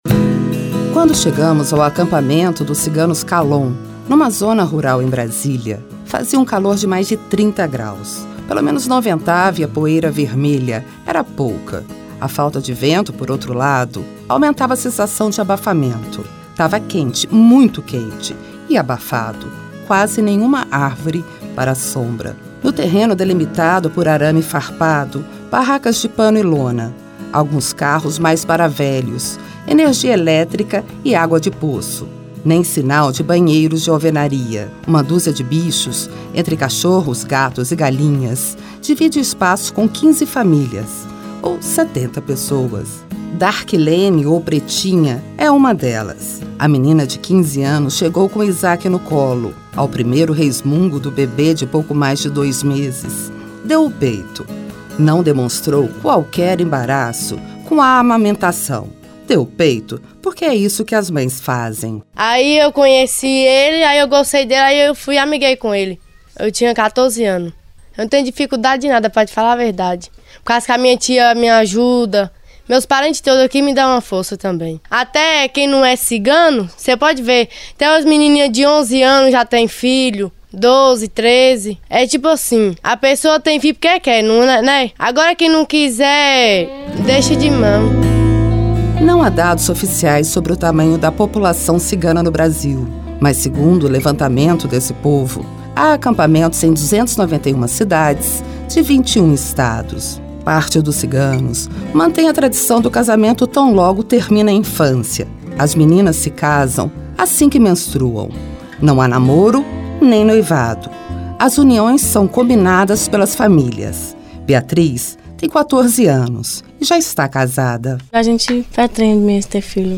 A entrevistas foram todas gravadas por equipes da TV Senado, uma vez que serão também aproveitadas na programação da emissora, em data ainda a ser definida.